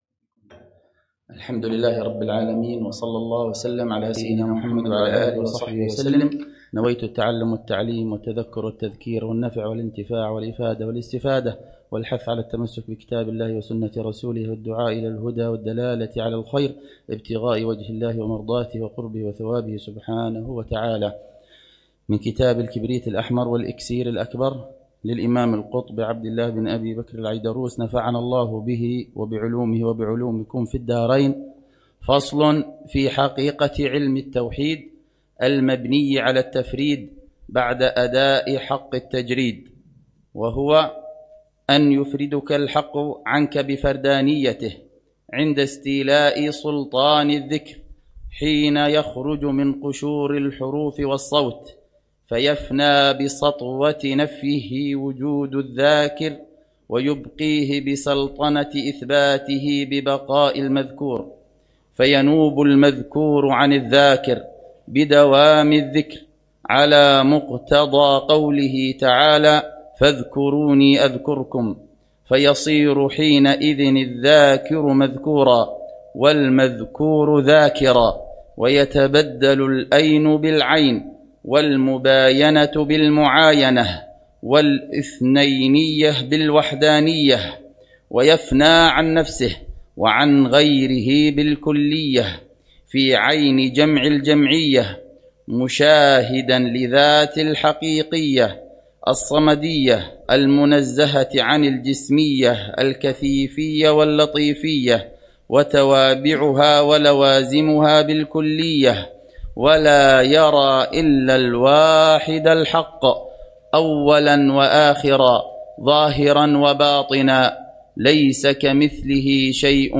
الدرس الثالث عشر للعلامة الحبيب عمر بن محمد بن حفيظ في شرح كتاب: الكبريت الأحمر و الأكسير الأكبر في معرفة أسرار السلوك إلى ملك الملوك ، للإمام